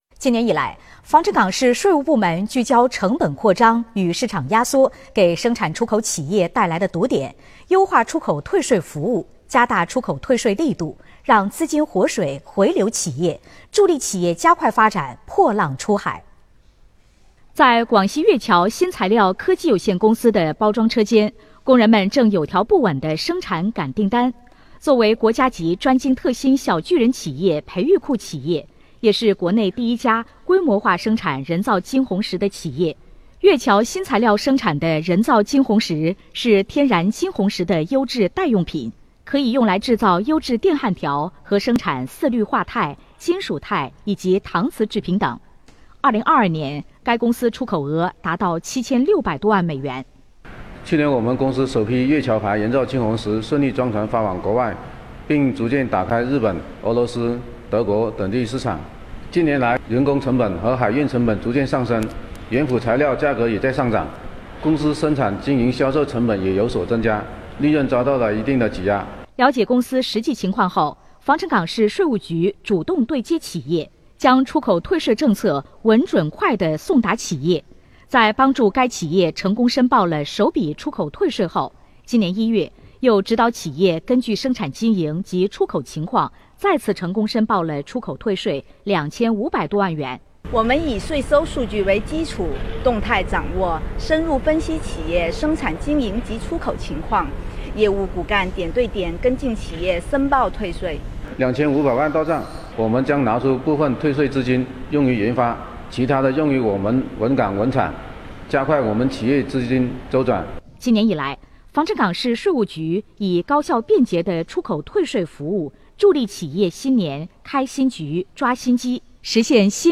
2023年2月5日，广西广播电视台《经济新观察》栏目报道《防城港：优化出口退税服务 助企纾困“破浪出海”，报道了近年以来防城港市税务部门聚焦成本扩张与市场压缩给生产出口企业带来的堵点，优化出口退税服务，加大出口退税力度，让资金活水回流企业，助力企业加快发展，“破浪出海”。
来源：广西网络广播电视台